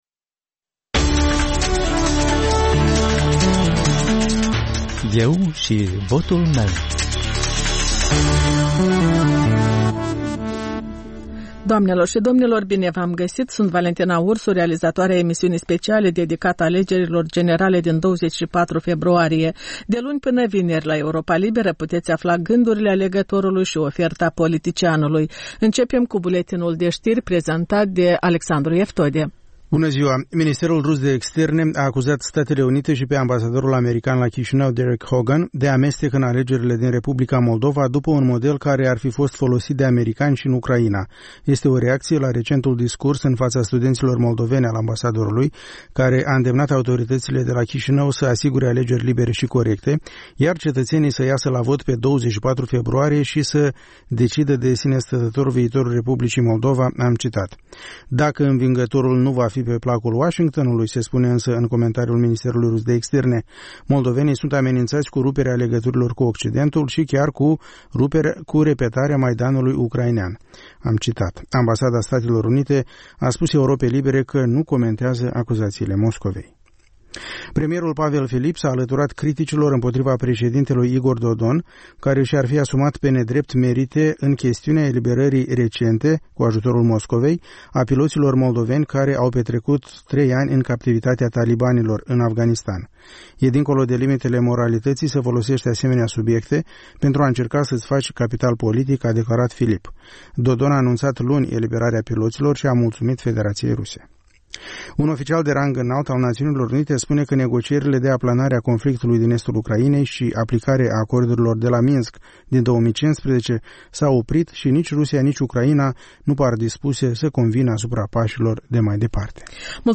Analize, interviuri cu candidați și cu potențiali alegători, sondaje de opinie, reportaje din provincie.